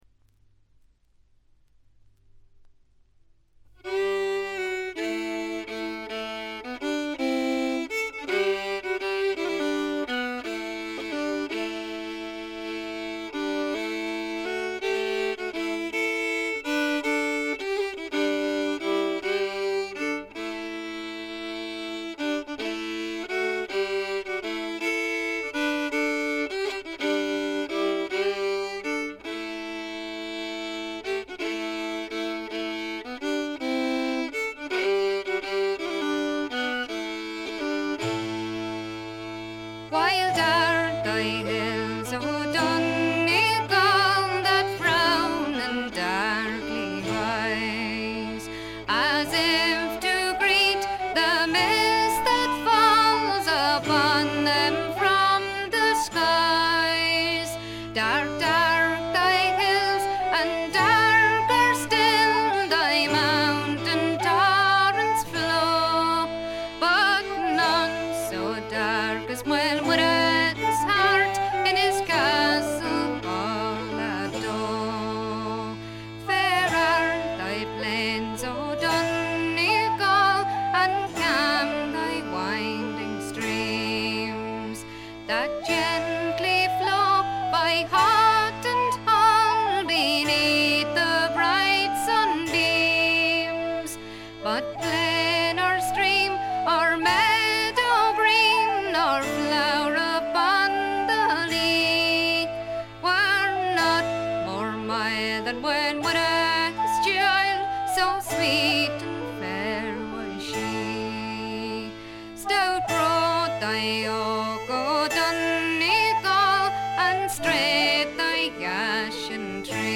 軽微なバックグラウンドノイズ程度。
時に可憐で可愛らしく、時に毅然とした厳しさを見せる表情豊かで味わい深いヴォーカルがまず最高です。
試聴曲は現品からの取り込み音源です。
vocals, harpsichord, bodhran